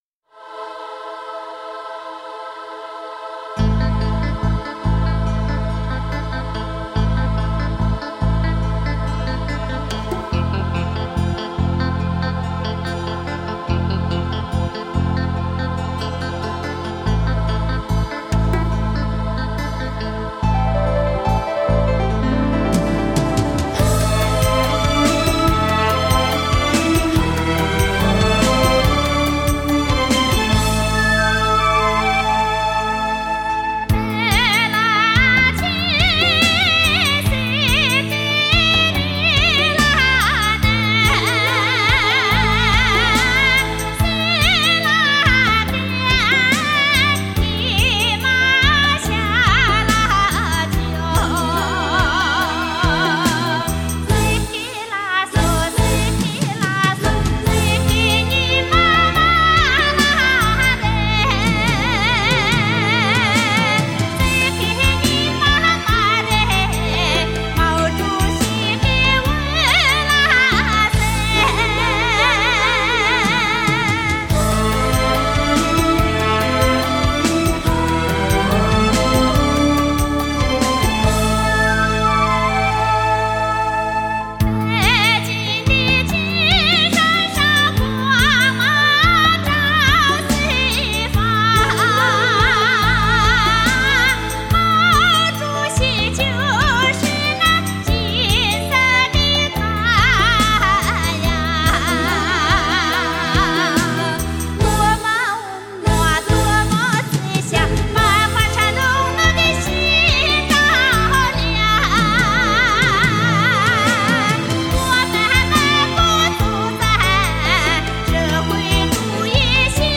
民歌经典永恒珍藏